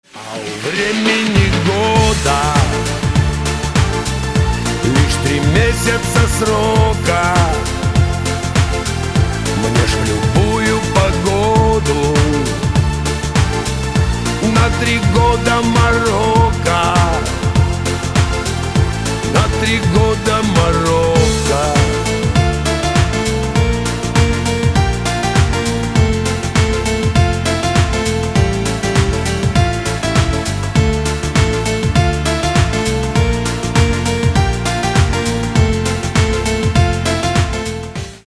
• Качество: 320, Stereo
мужской вокал
спокойные
клавишные
пианино
русский шансон
тюремные